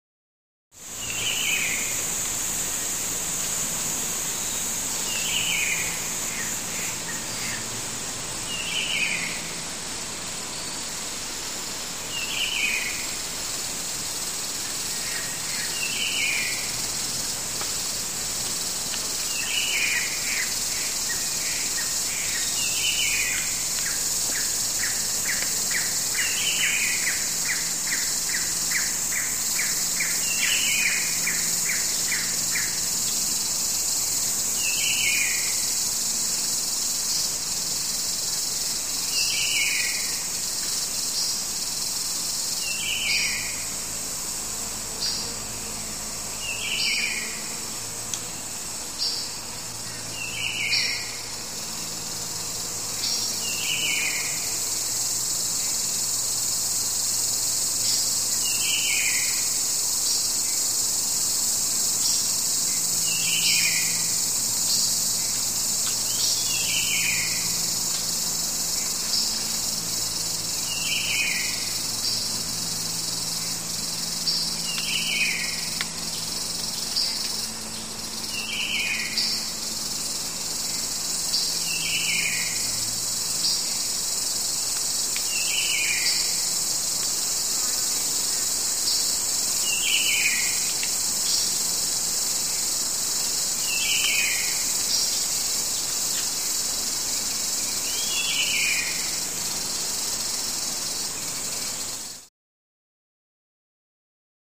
Jungle Atmosphere; Amazon Rain Forest Atmosphere. Bird Calls, One Species Close To Mic, Insect Sizzle And Occasional Fly Past.